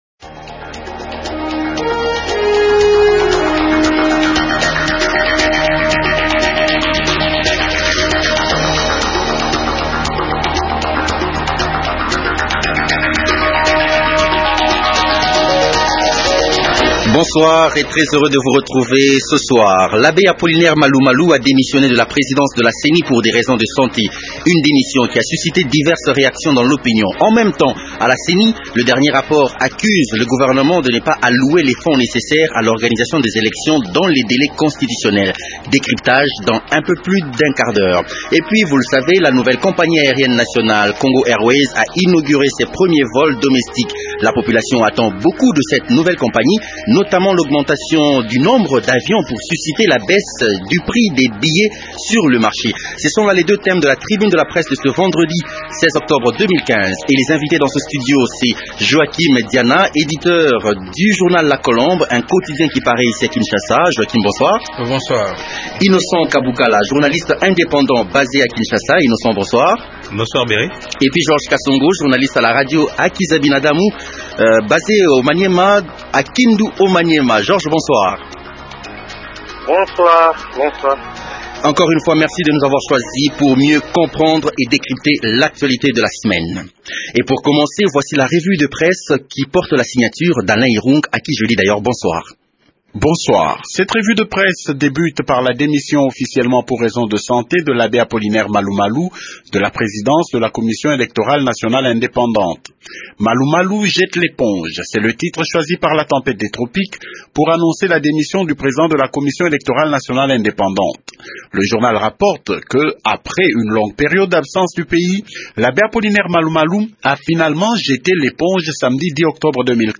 Journaliste indépendant basé à Kinshasa.